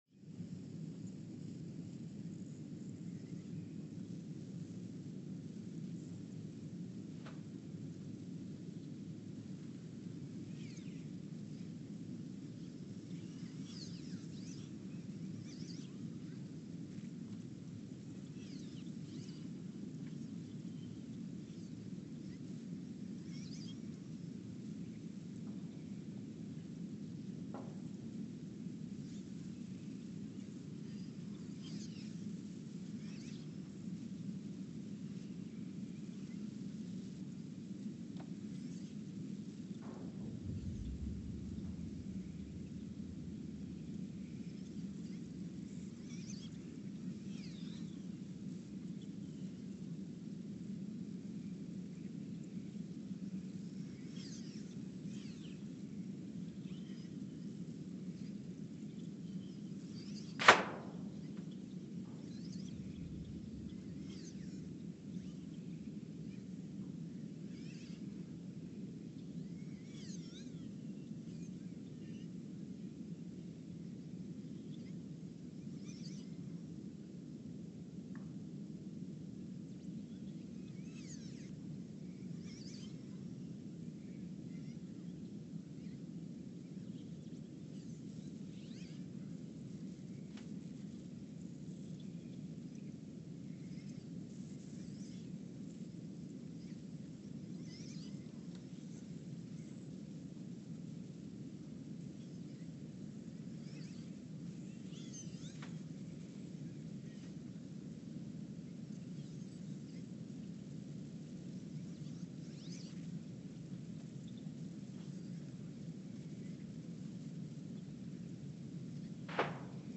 Ulaanbaatar, Mongolia (seismic) archived on December 17, 2023
Sensor : STS-1V/VBB
Speedup : ×900 (transposed up about 10 octaves)
Loop duration (audio) : 03:12 (stereo)
Gain correction : 25dB